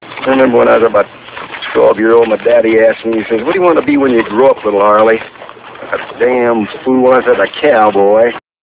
Cowboy.real audio-10kbHarley talking about himsef as a boy and what he wanted to be when he grew up